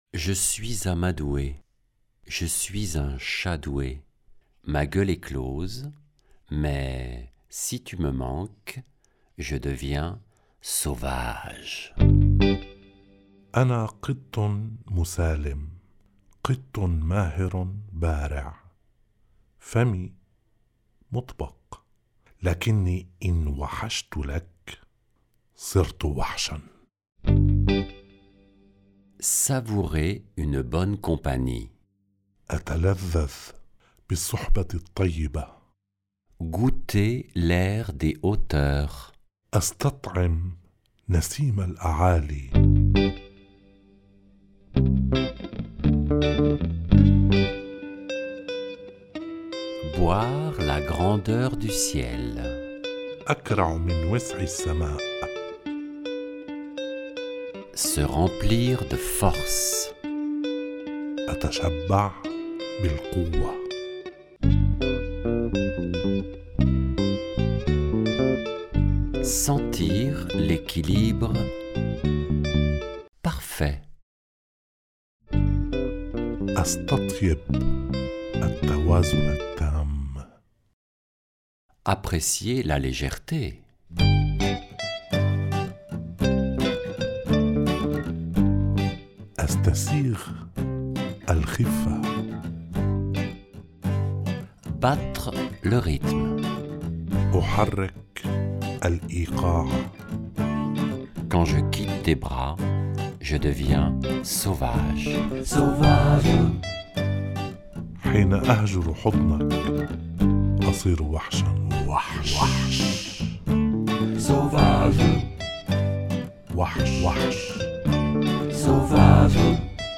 création musicale
lecture en français
lecture en arabe